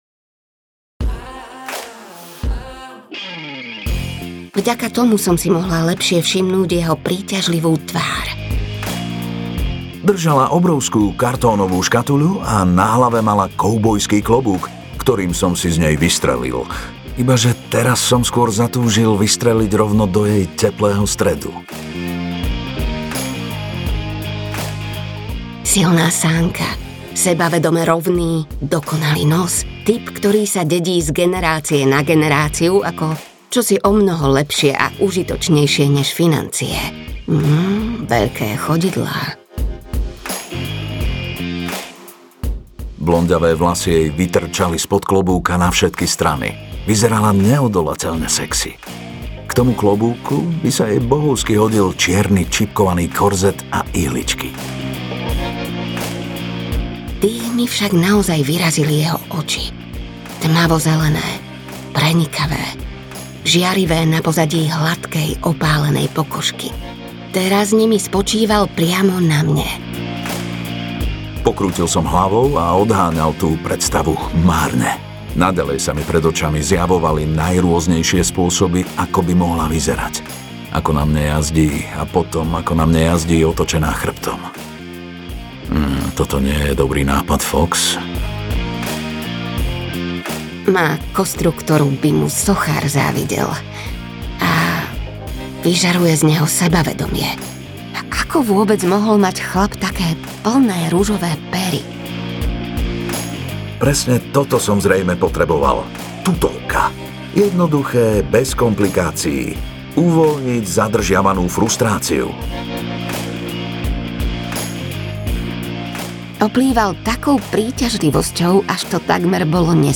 Nemali by sme audiokniha
Ukázka z knihy